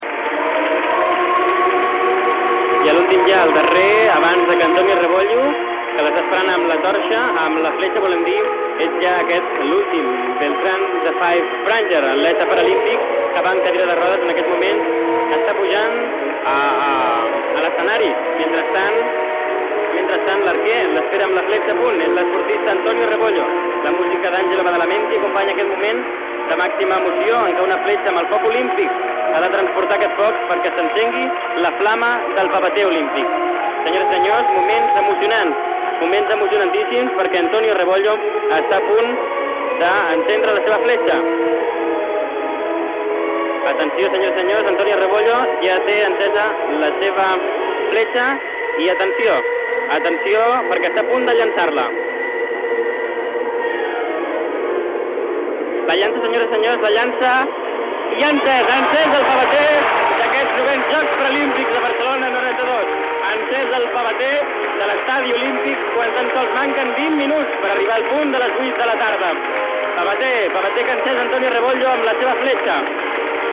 Inici de la transmissió de la cerimònia inaugural dels Jocs Paralimpics Barcelona 1992.
Informatiu